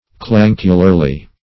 Clancularly \Clan"cu*lar*ly\, adv. privately; secretly.